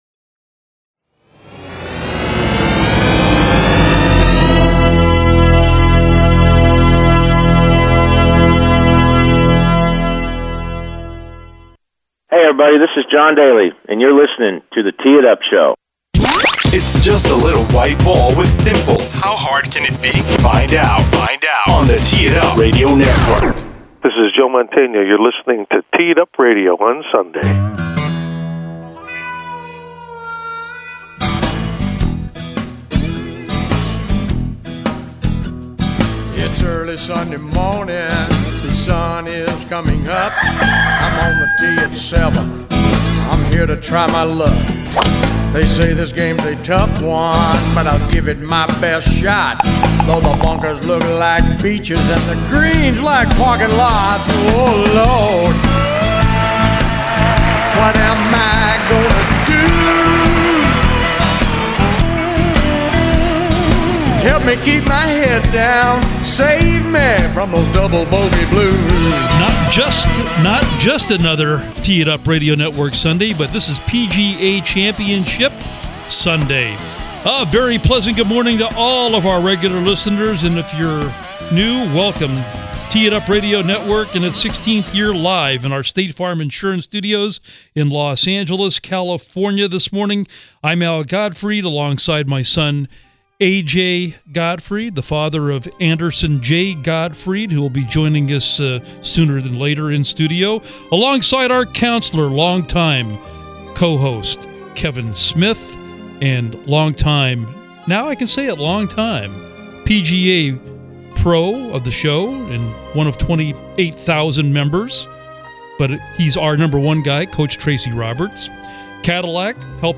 interveiw